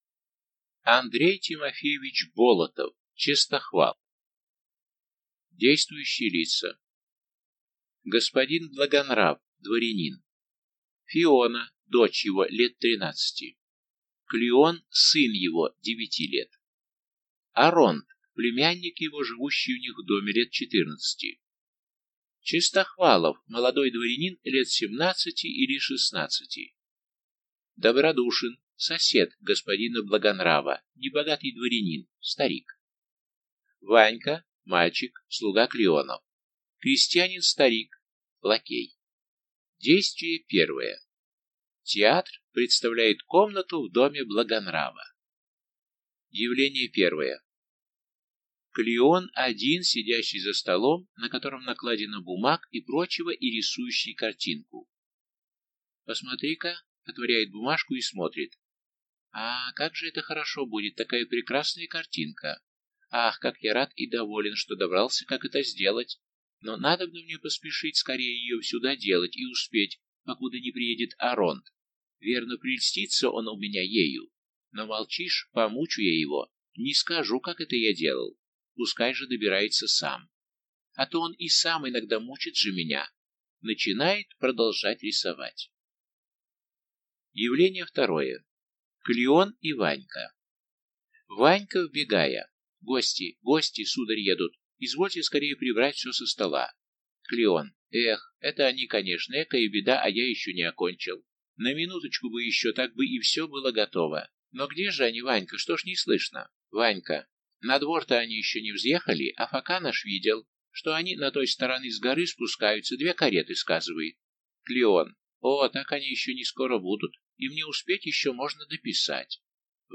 Аудиокнига Честохвал | Библиотека аудиокниг